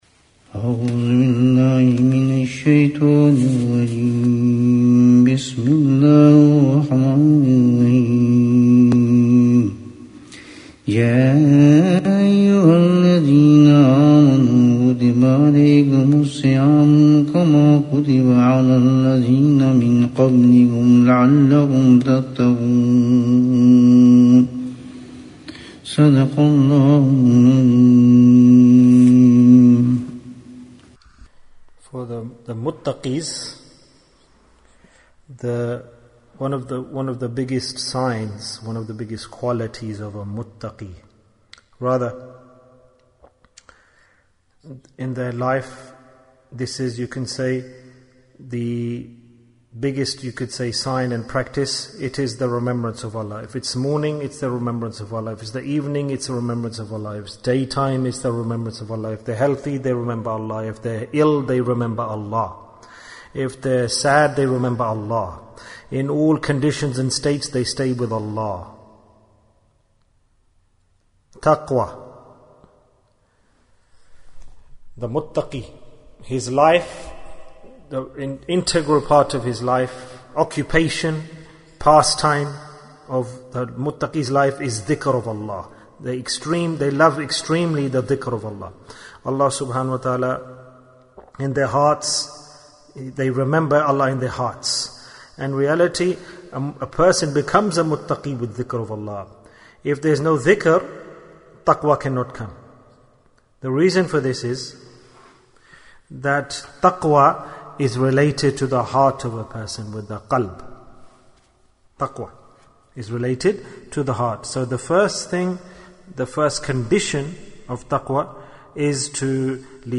The Most Favourable Deed of Mutaqeen Bayan, 26 minutes8th May, 2020